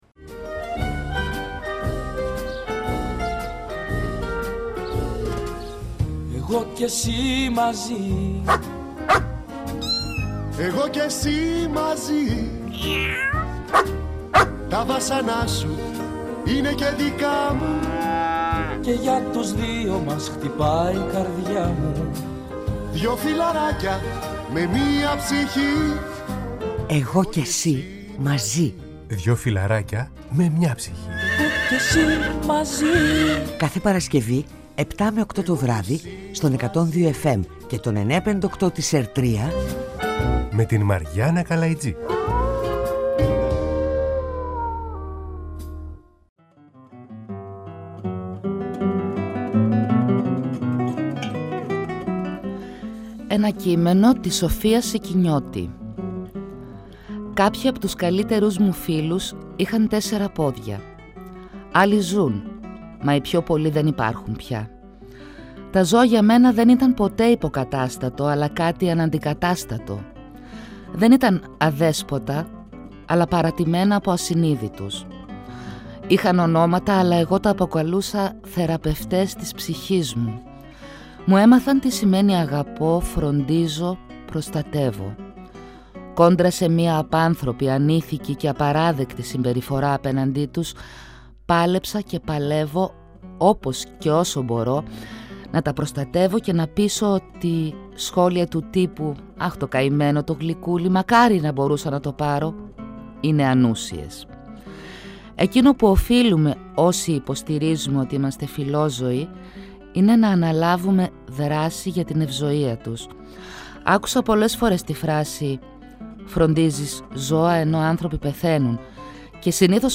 ήρθε στο στούντιο και μας μίλησε για αυτή την προσπάθεια ζωής που κάνει και τα μεγάλα προβλήματα που υπάρχουν